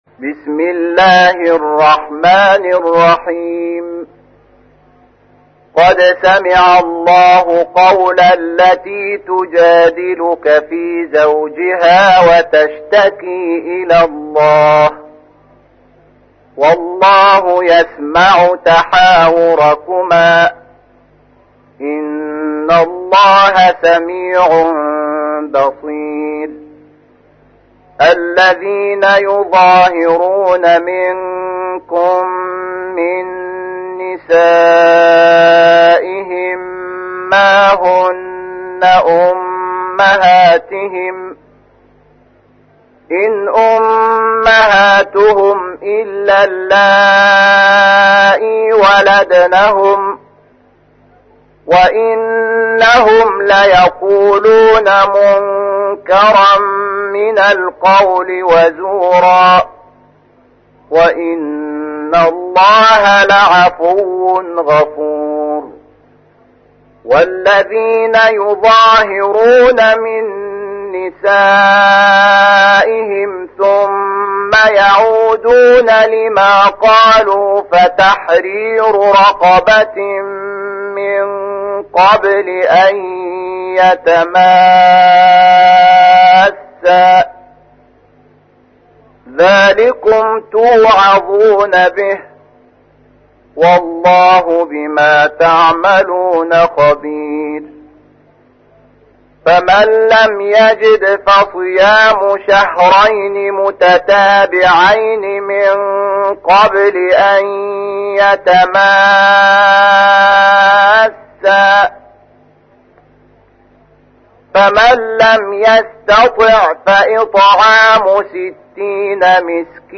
تحميل : 58. سورة المجادلة / القارئ شحات محمد انور / القرآن الكريم / موقع يا حسين